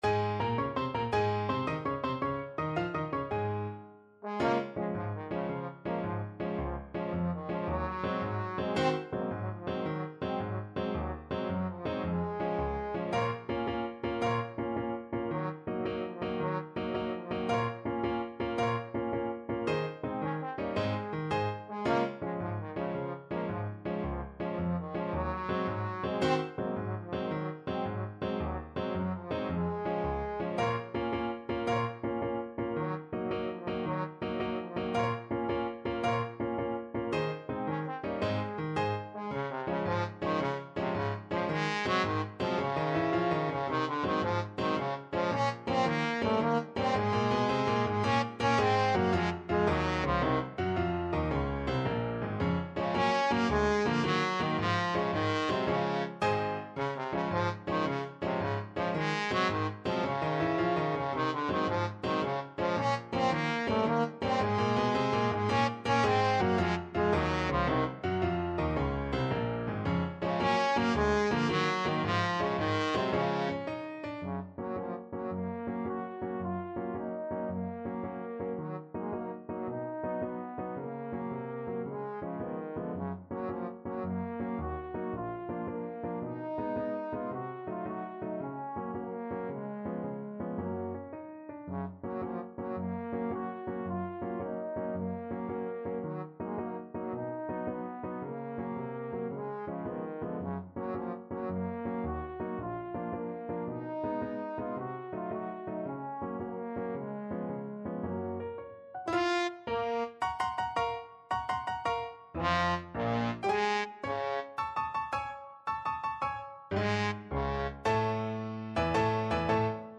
Classical Sousa, John Philip King Cotton March Trombone version
Trombone
Db major (Sounding Pitch) (View more Db major Music for Trombone )
6/8 (View more 6/8 Music)
Classical (View more Classical Trombone Music)